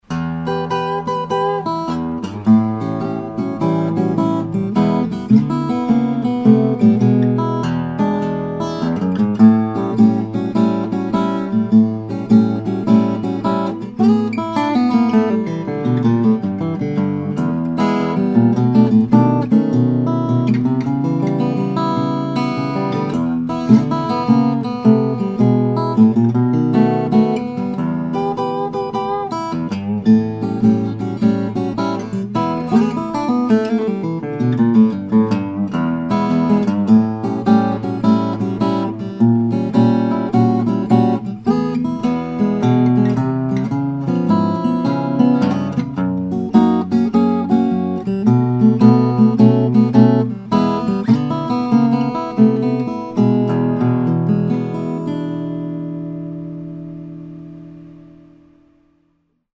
1930s Regal 0-21 Rosewood/Adirondack - Dream Guitars
Blues and Rag players will also love it’s big, bold sound.